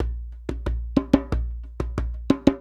90 JEMBE4.wav